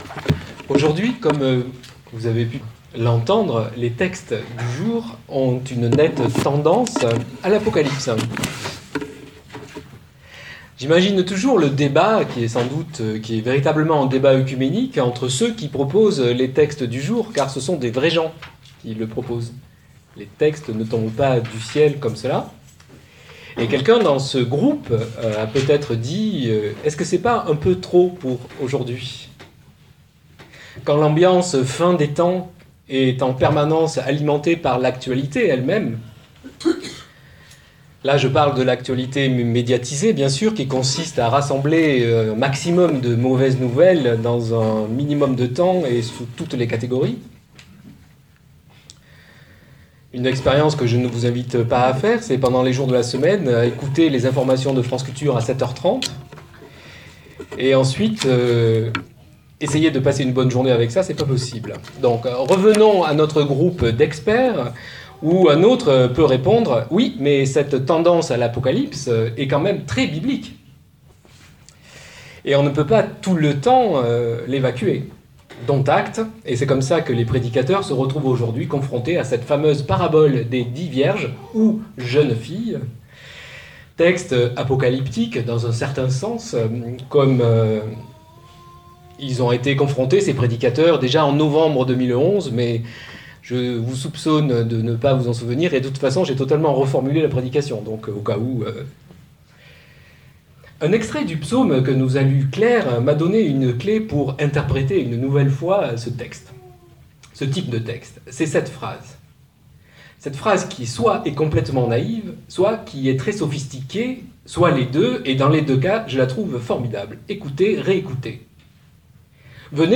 LES DIX JEUNES FILLES, PREDICATION DU 9 NOVEMBRE 2014, MAISON FRATERNELLE